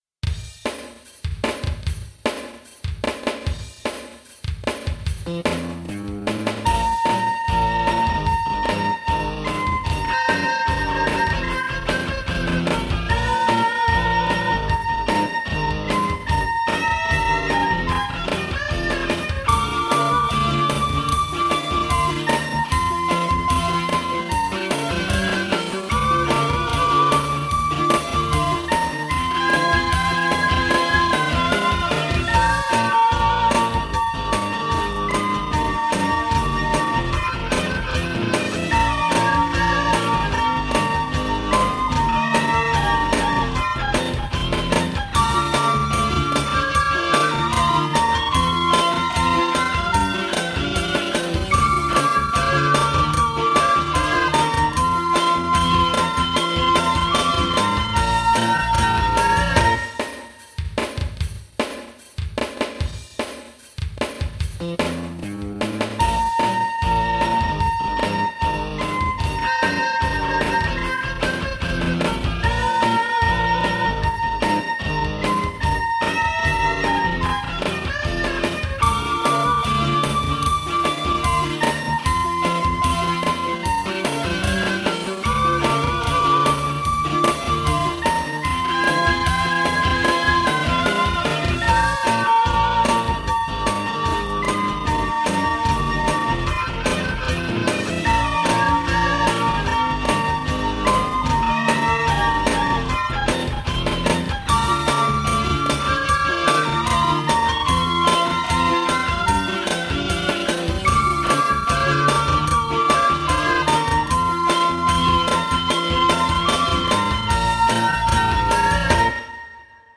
Rocket (fast) melody 2x.mp3